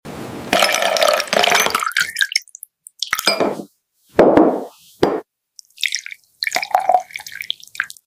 The ultimate AI ASMR iced sound effects free download
Watch as rich, whole milk and viscous hot chocolate are poured over crackling ice cubes in this oddly satisfying animation. The beautiful layered swirls and crisp, relaxing sounds of pouring and ice clinking create the perfect sensory treat for chocolate lovers.